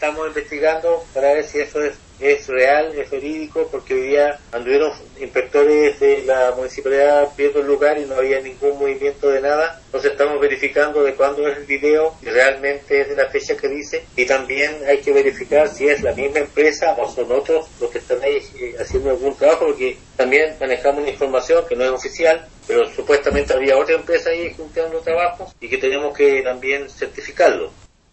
La denuncia fue recepcionada por el municipio de Osorno, quien manifestó que se encuentran indagando el registro audiovisual, para conocer si corresponde a la fecha mencionada o es anterior a la orden de paralizar la obra. Así lo indicó el jefe comunal, Emeterio Carrillo, quien sostuvo que podría tratarse de otra empresa que está colindante al terreno afectado y no precisamente a Baluart.